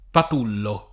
patullo [ pat 2 llo ]